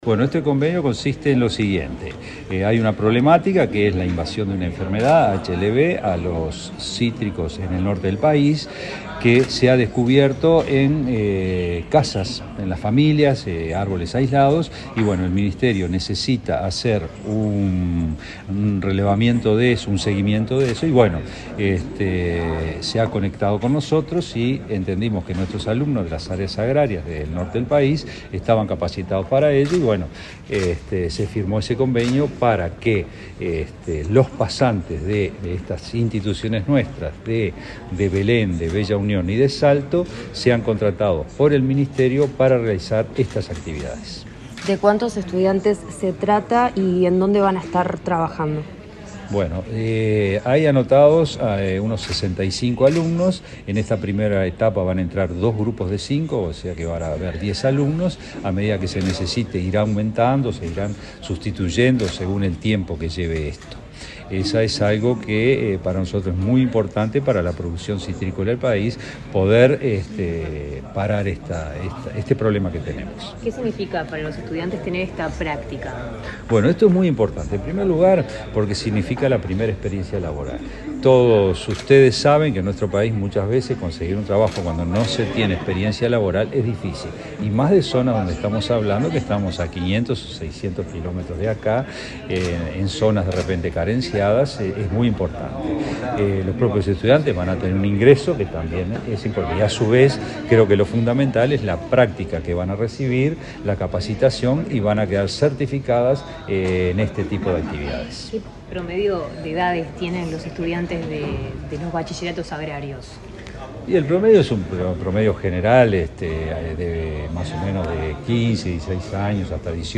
Declaraciones del director general de UTU
El director general de la UTU, Juan Pereyra, dialogó con la prensa luego de firmar un convenio con el ministro de Ganadería, Fernando Mattos, para que